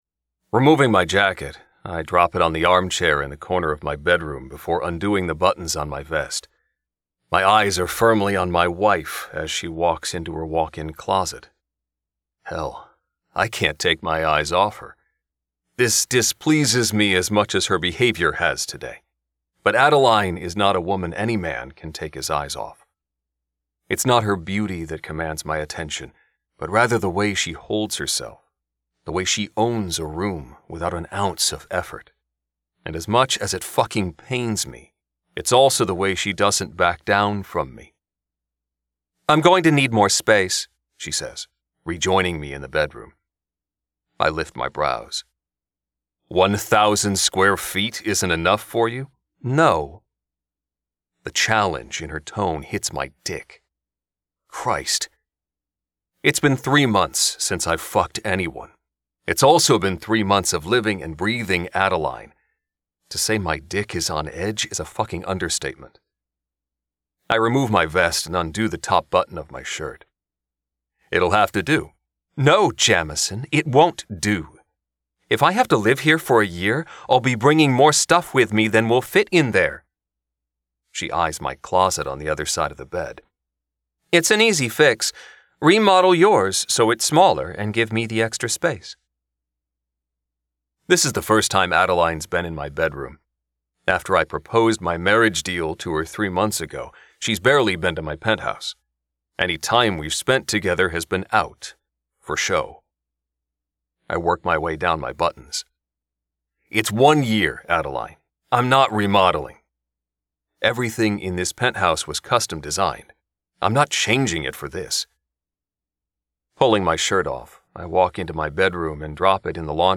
• Audiobook